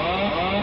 boeing707AutopilotDisconnected.ogg